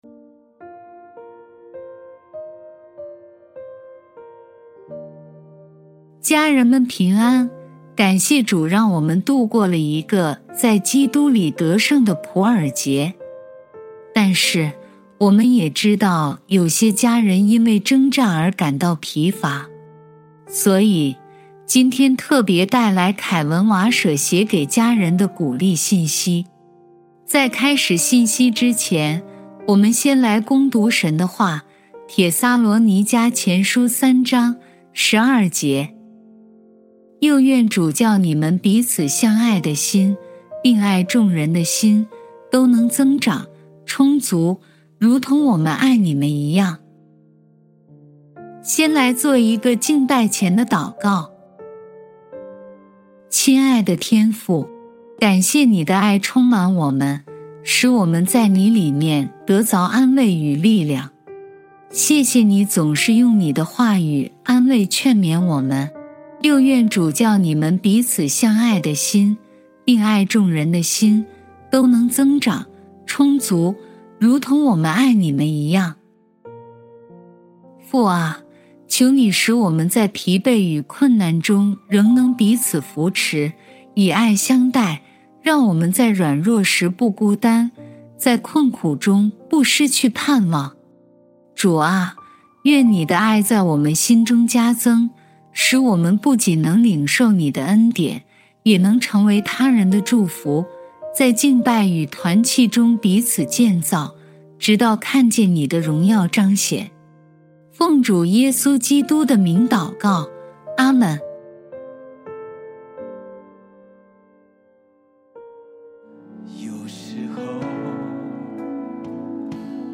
微牧之歌录音朗读